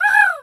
pgs/Assets/Audio/Animal_Impersonations/crow_raven_call_squawk_07.wav
crow_raven_call_squawk_07.wav